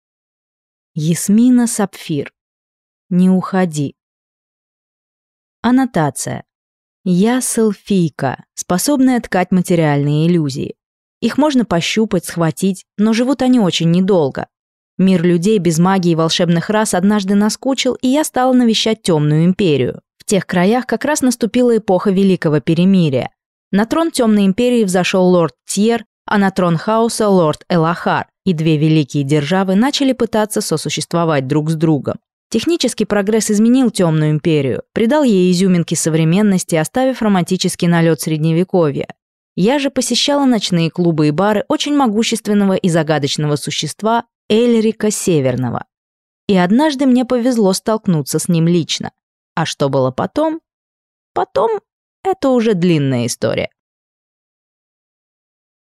Aудиокнига Не уходи